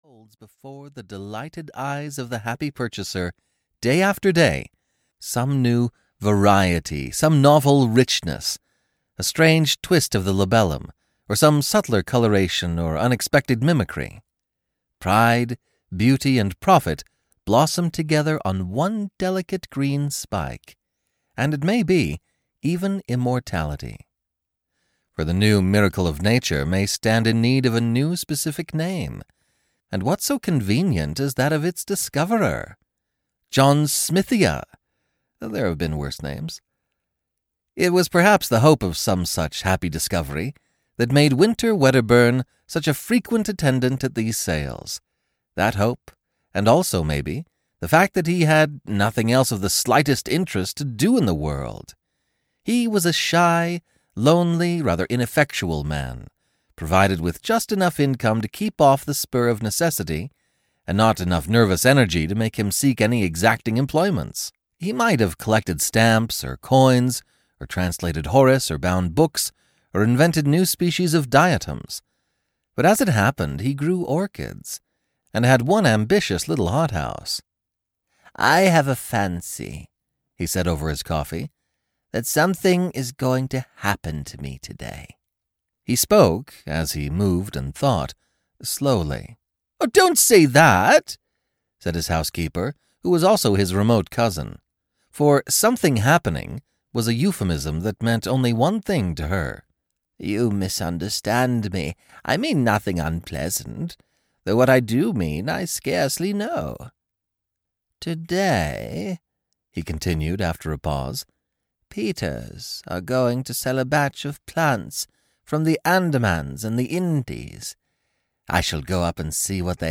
Audiobook The Flowering of the Strange Orchid by H. G. Wells.
Ukázka z knihy